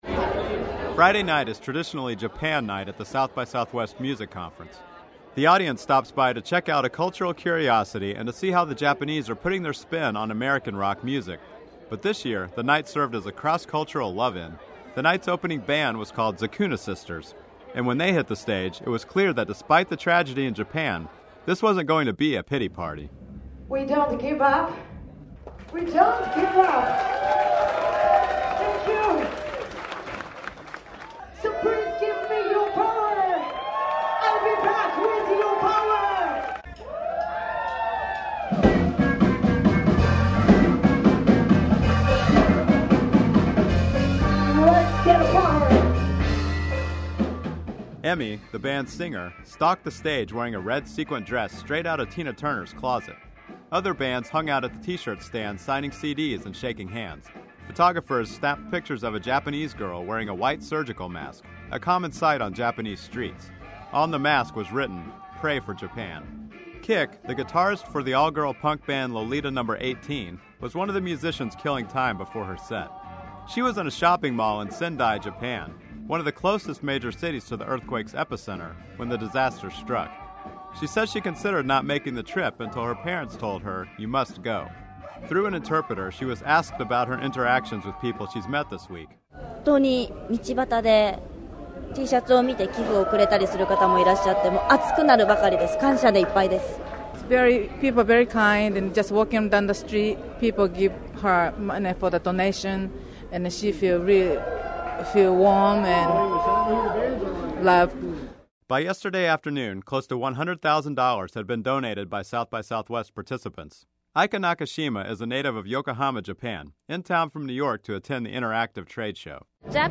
• KERA Radio Story: